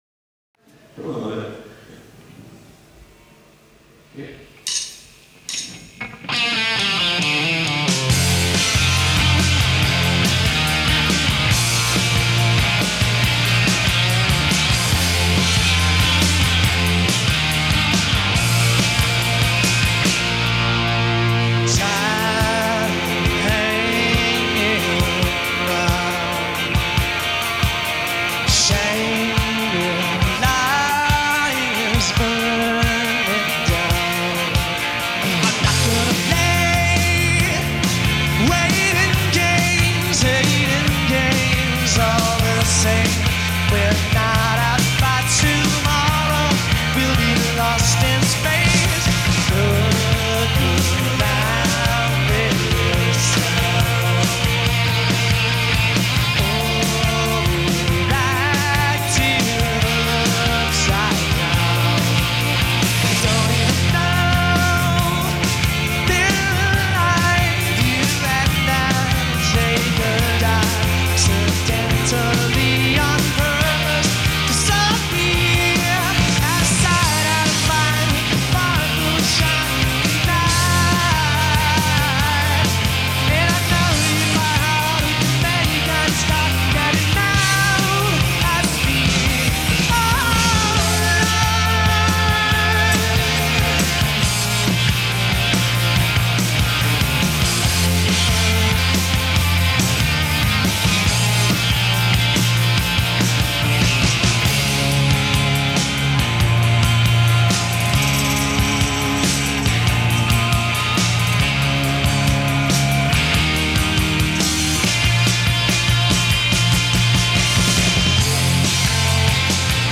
enregistrée le 08/06/1993  au Studio 105